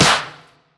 • Short Reverb Clap Sound Clip D Key 07.wav
Royality free clap one shot - kick tuned to the D note. Loudest frequency: 2769Hz
short-reverb-clap-sound-clip-d-key-07-0S9.wav